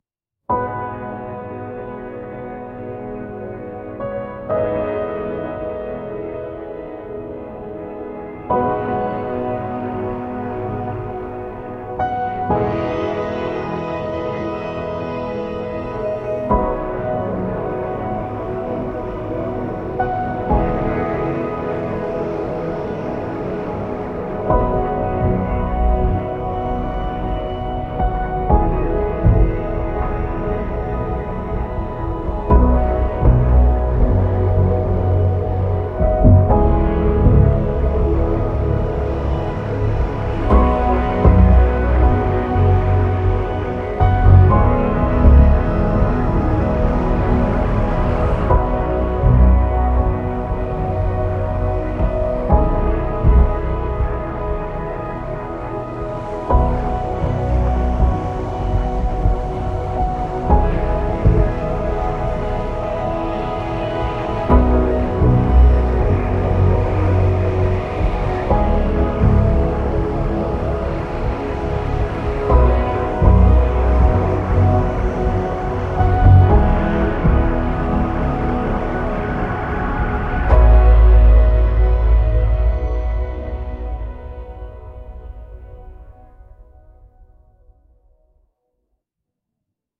ダウンロードフリー無料のBGM素材・音楽素材を提供しています。